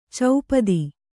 ♪ caupadi